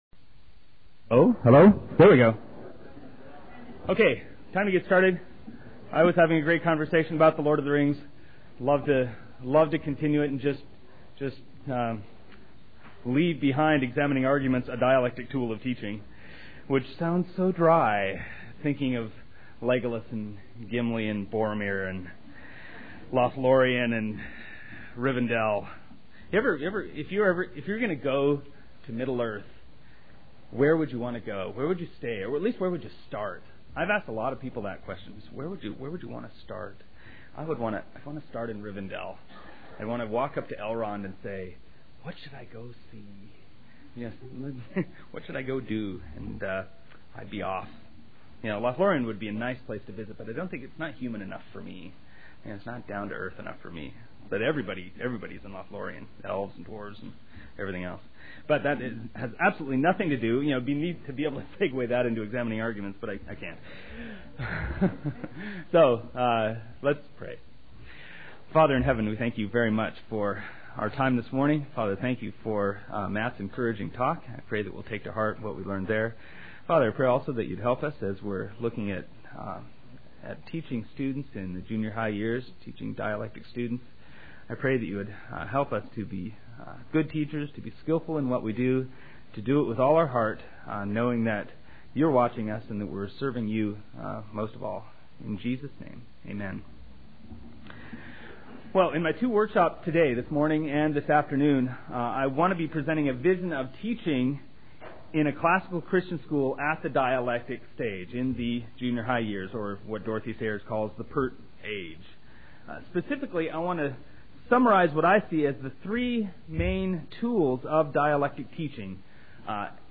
2002 Workshop Talk | 0:51:28 | 7-12, Logic